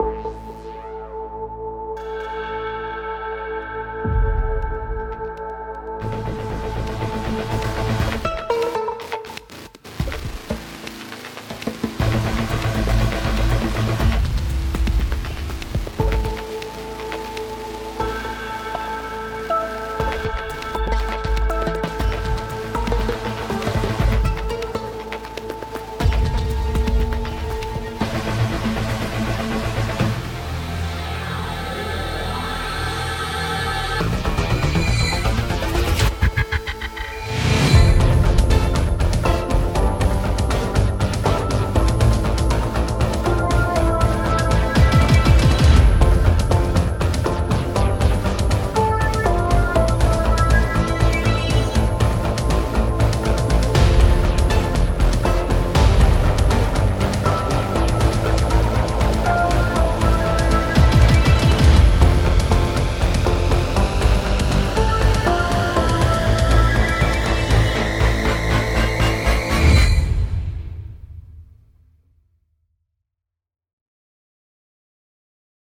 100 inspired high quality sci-fi presets like Drones, Pads, Sequences, FXs and others.
• Explore new sonic horizons: Immerse yourself in a universe of ethereal drones, lush pads, hypnotic sequences, and special effects that will bring your compositions to life.
• * The video and audio demos contain presets played from Nebula Echoes sound bank, every single sound is created from scratch with Spire.
• * All sounds of video and audio demos are from Nebula Echoes (except drums, bass and additional arrangements).